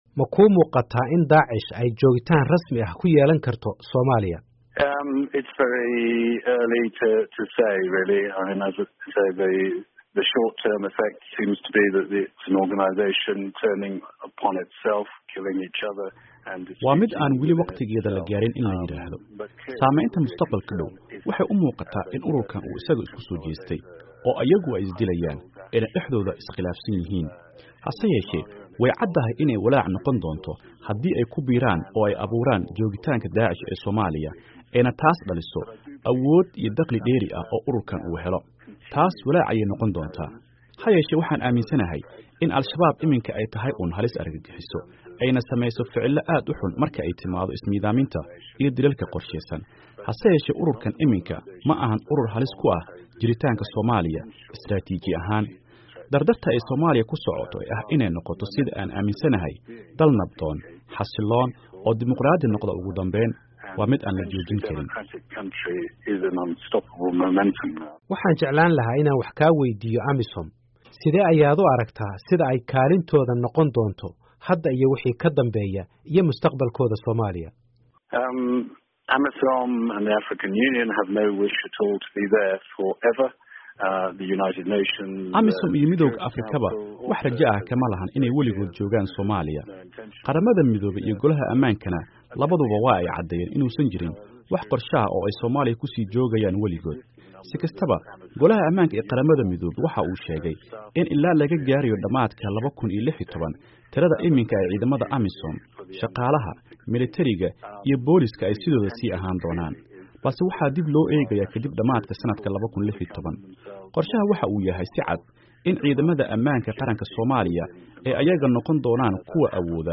Qeybta labaad ee wareysiga Kay